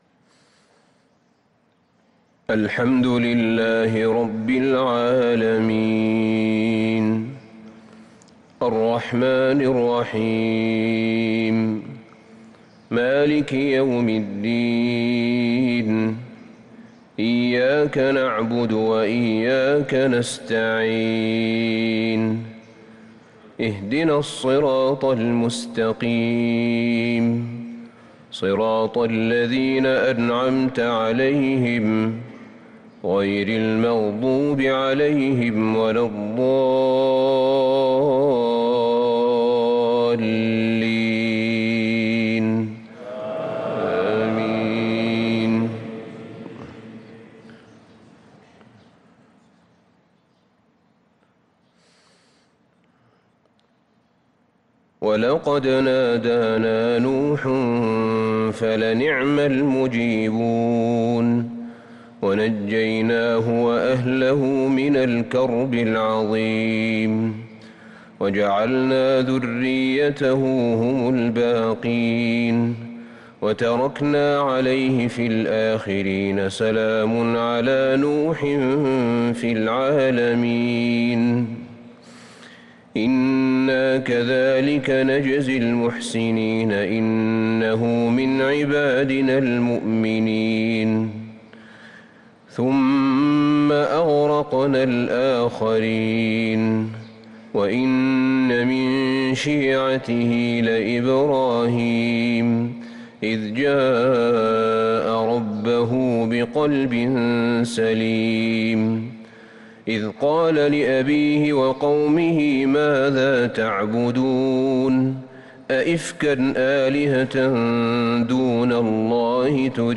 صلاة الفجر للقارئ أحمد بن طالب حميد 16 محرم 1444 هـ
تِلَاوَات الْحَرَمَيْن .